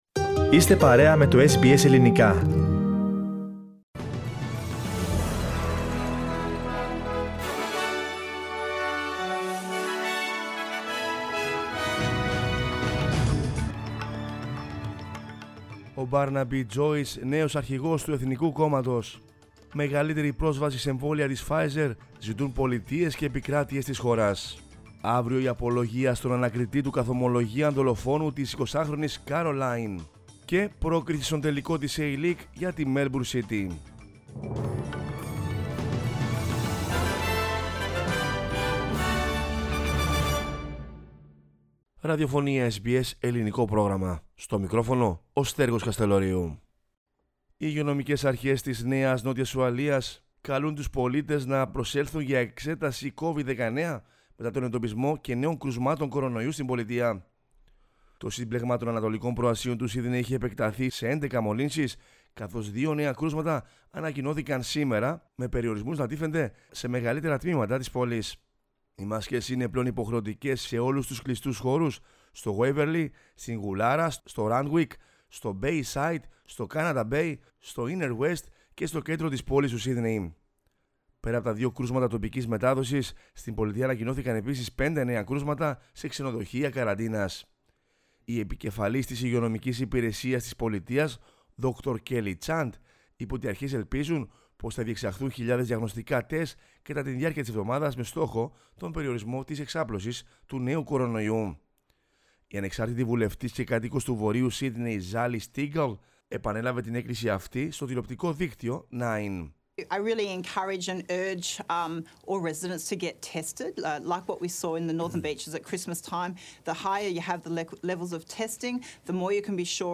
News in Greek from Australia, Greece, Cyprus and the world is the news bulletin of Monday 21 June 2021.